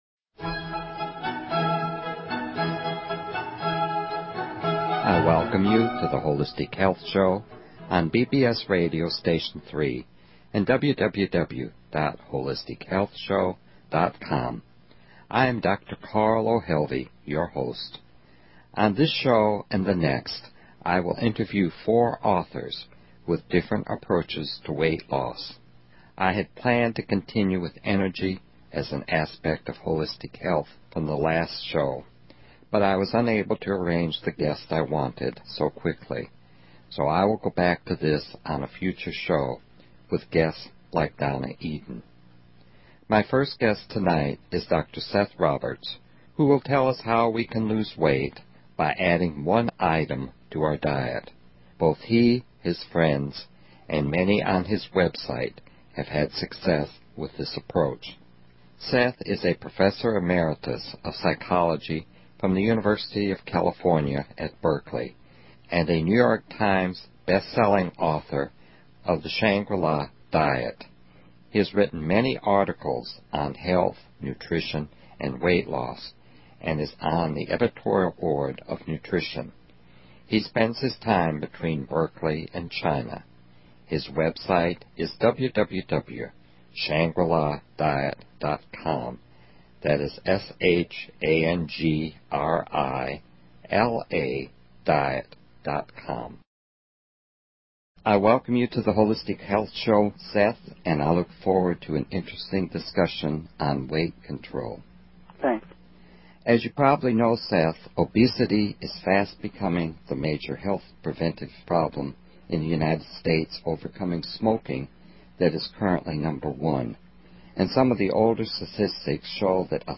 Talk Show Episode, Audio Podcast, The_Holistic_Health_Show and Courtesy of BBS Radio on , show guests , about , categorized as
A sample of the interview follows.